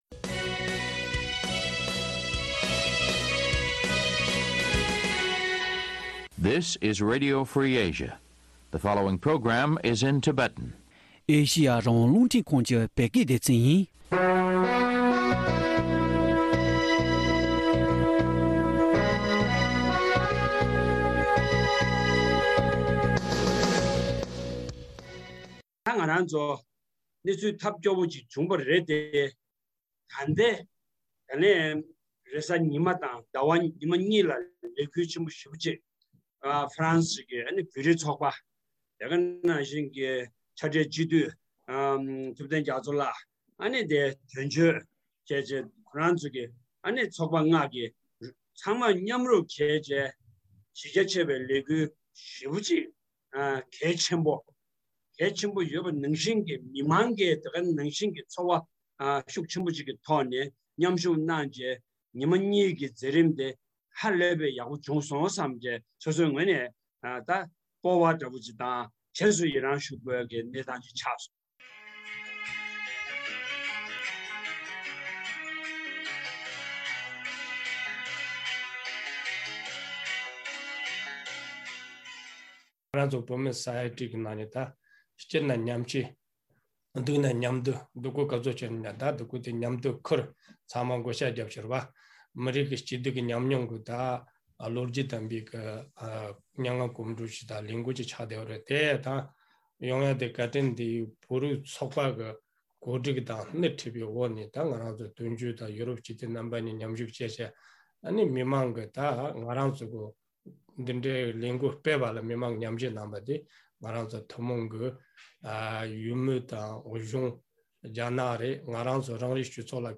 ཉེ་ཆར་ཧྥརནསི་ཡི་ནང་བོད་མི་ཞིག་རྐྱེན་འདས་སུ་བཏང་བའི་གནད་དོན་ཐད་འབྲེལ་ཡོད་དང་ལྷན་གླེང་མོལ་ཞུས་པ།